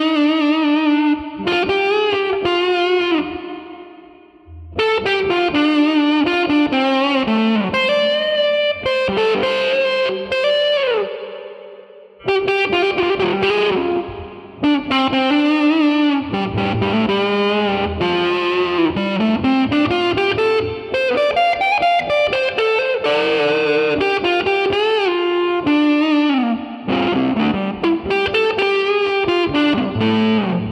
125 Bpm主音吉他
Dm7/Gm7/Em7/A7
Tag: 125 bpm Rock Loops Guitar Electric Loops 2.58 MB wav Key : D